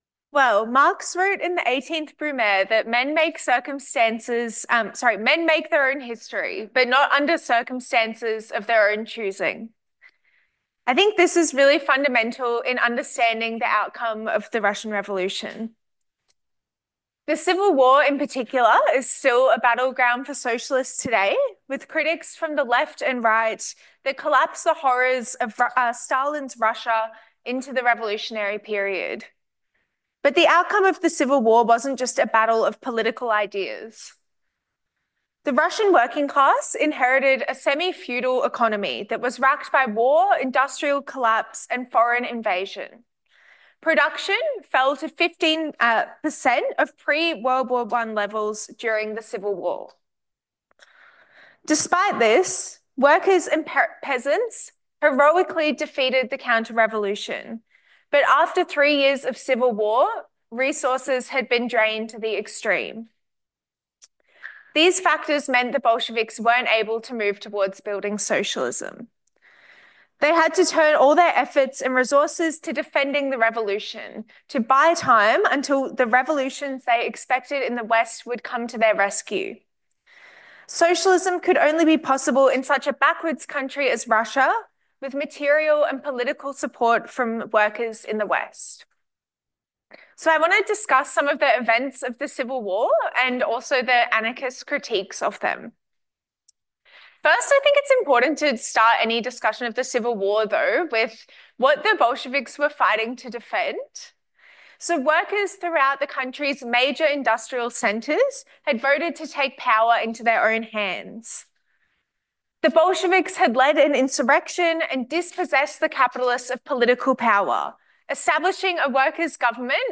Socialism 2025 (Brisbane) Play talk